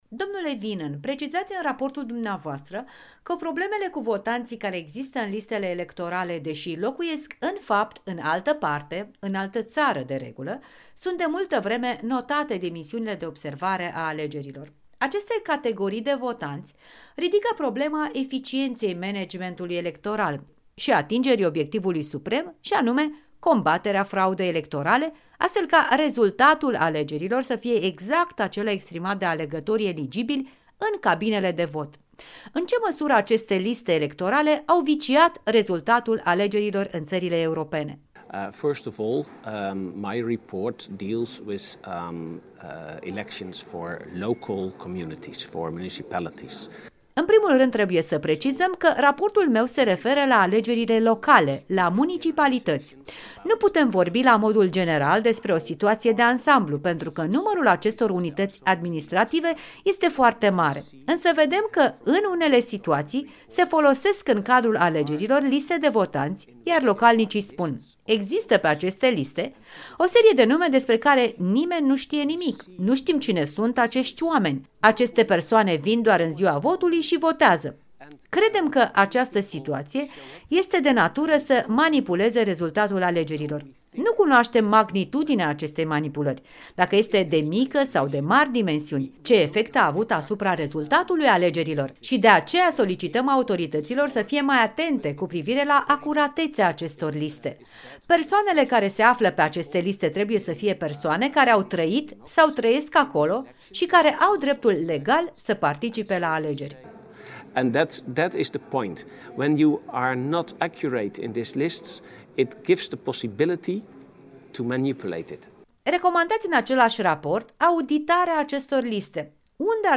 Interviu cu Jos Wienen